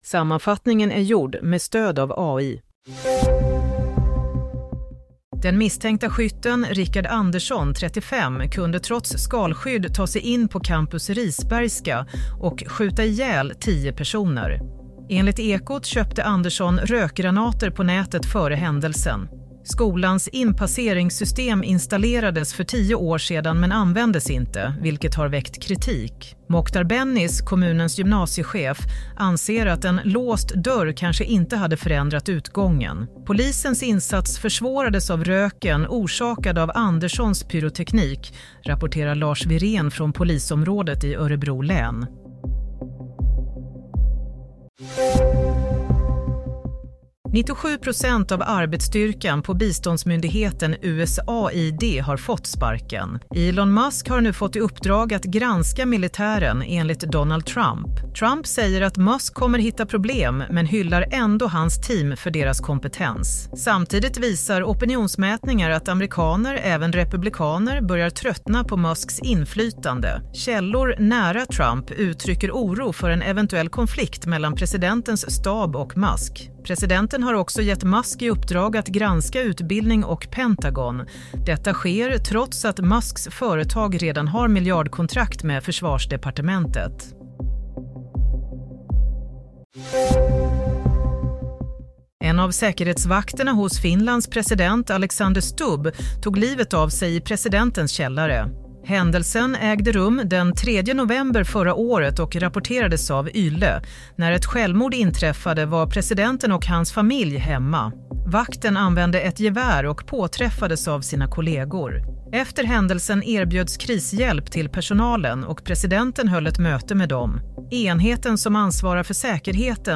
Nyhetssammanfattning - 8 februari 07:30